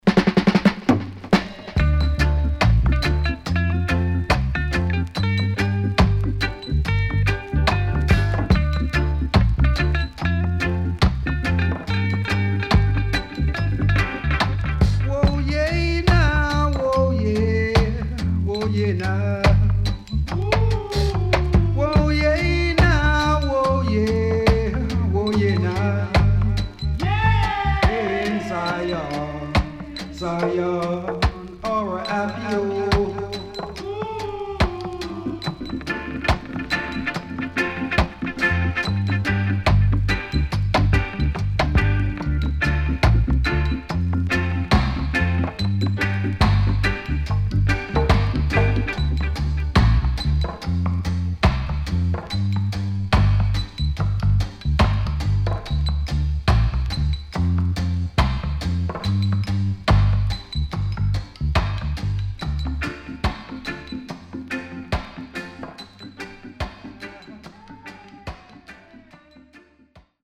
Great Killer Roots & Dubwise
SIDE A:少しチリノイズ入ります。